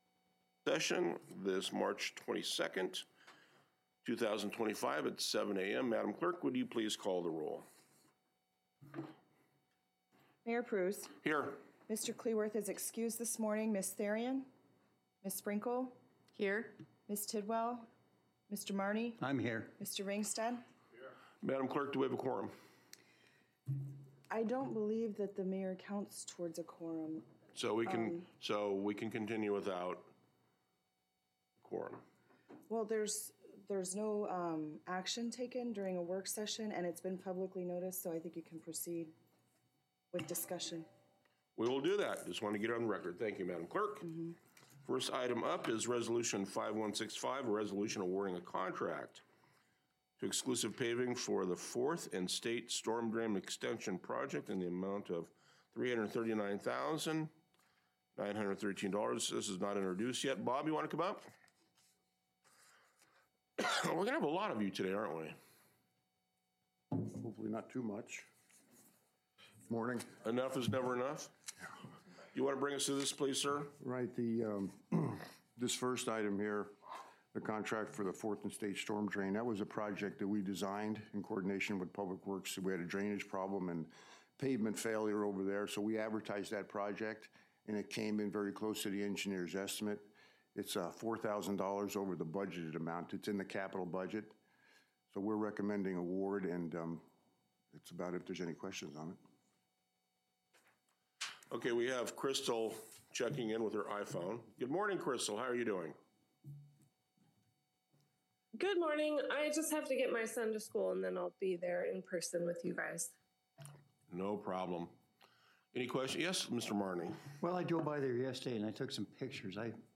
Regular City Council Work Session
Location Patrick B Cole City Hall 800 Cushman Street Fairbanks, AR 99701